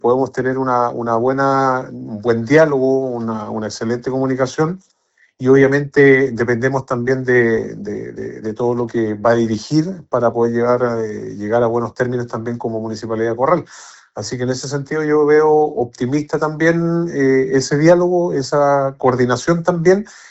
El alcalde de Corral, Claudio González, se mostró optimista por la coordinación con la futura delegada presidencial, asegurando que se puede tener un buen diálogo para fortalecer a la comuna puerto.
alcalde-corral-nueva-delegada.mp3